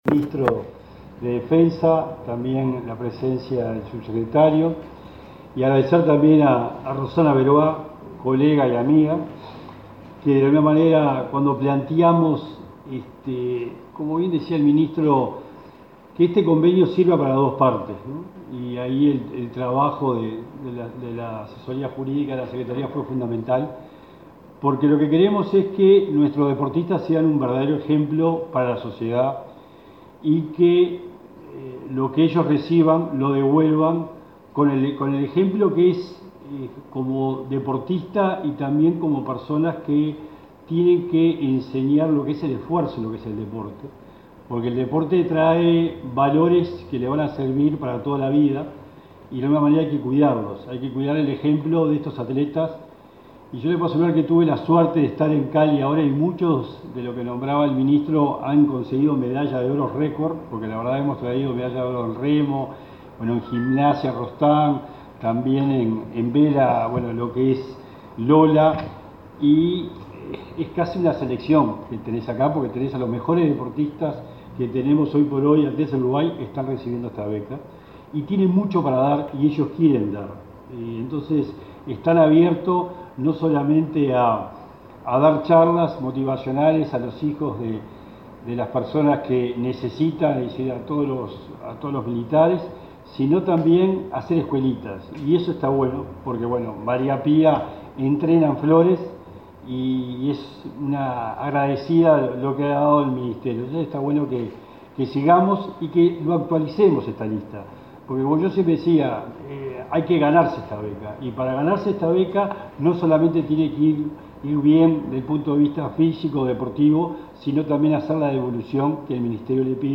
Palabras del secretario nacional del Deporte y del ministro Javier García